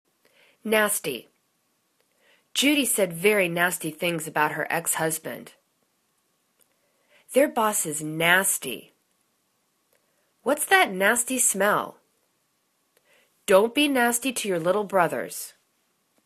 nas.ty     /'nasti/    adj